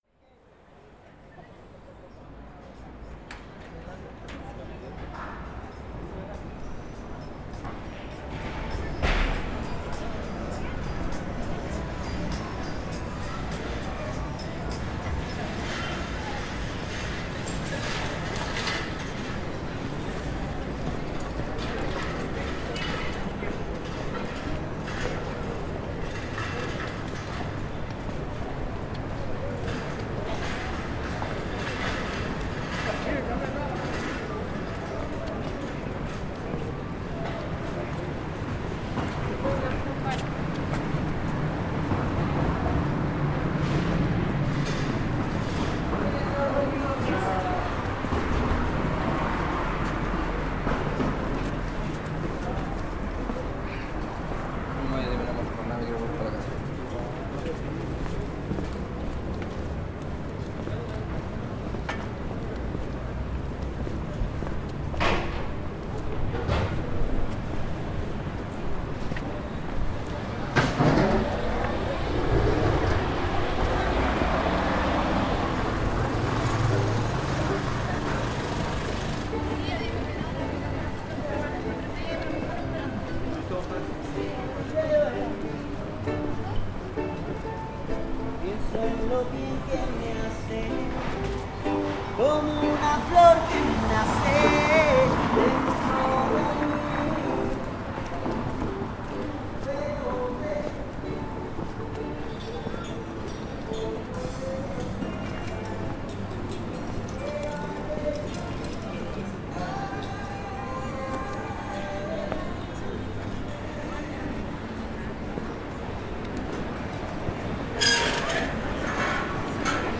enregistrement live